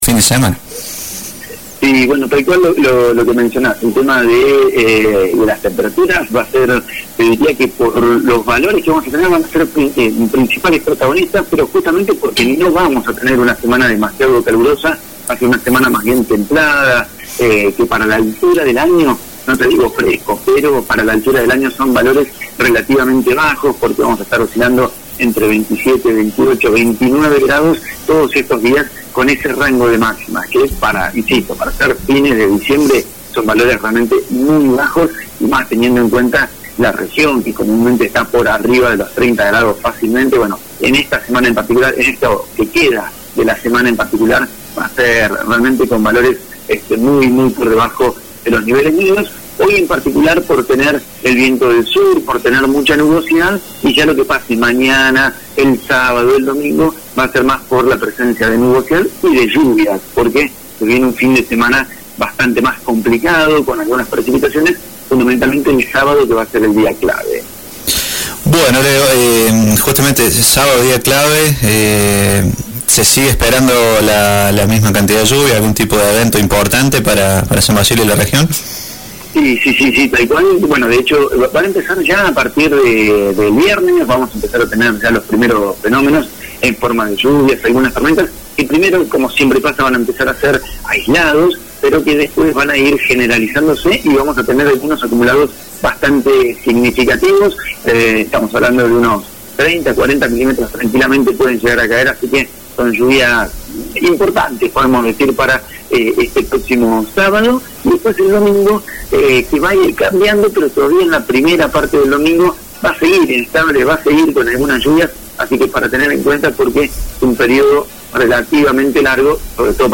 Jueves: El tiempo en San Basilio y la región